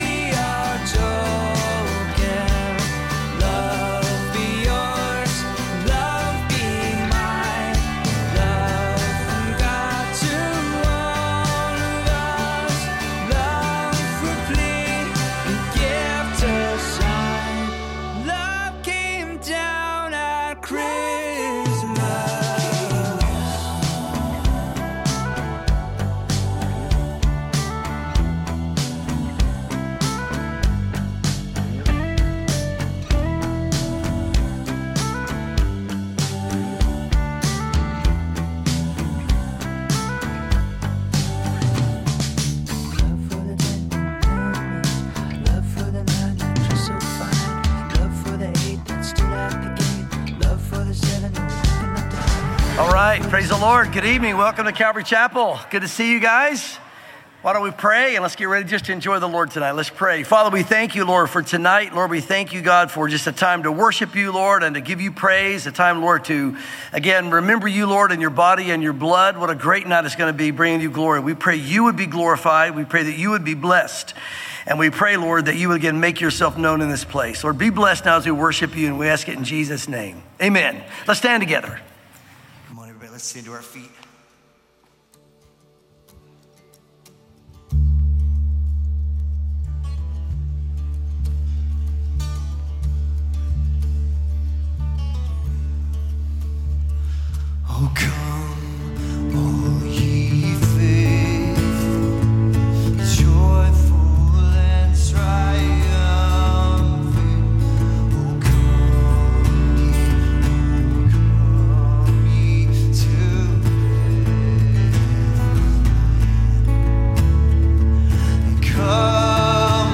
Calvary Knoxville Midweek Live!